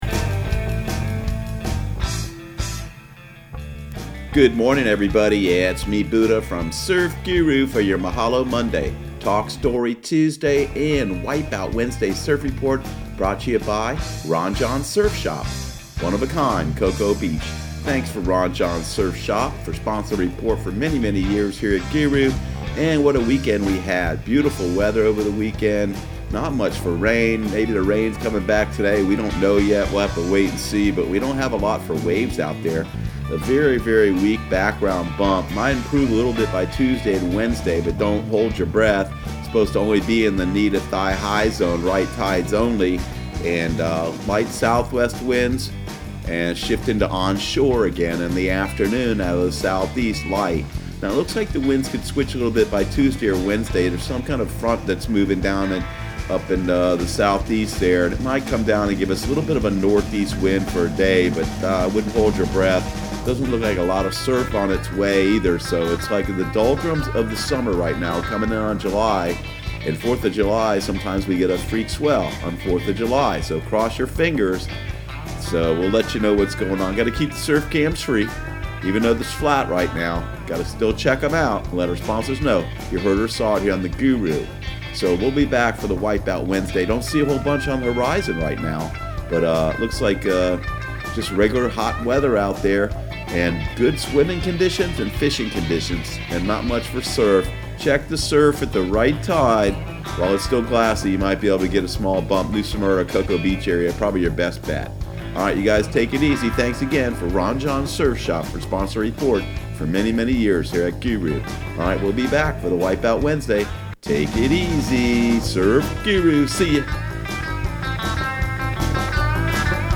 Surf Guru Surf Report and Forecast 06/24/2019 Audio surf report and surf forecast on June 24 for Central Florida and the Southeast.